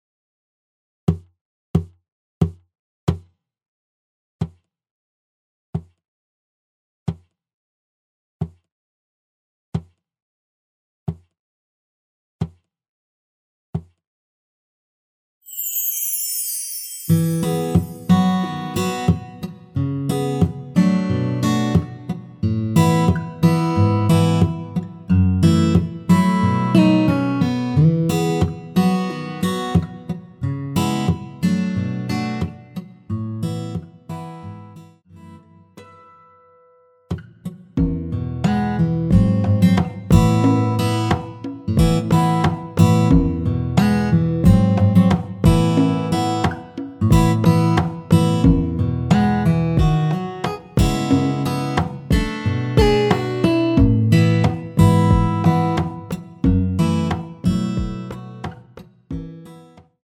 노래 들어가기 쉽게 전주 1마디 넣었으며
노래 시작 앞부분이 무반주라서 기타 바디 어택으로
박자 맞추기 쉽게 넣었습니다.(미리듣기 확인)
앞부분30초, 뒷부분30초씩 편집해서 올려 드리고 있습니다.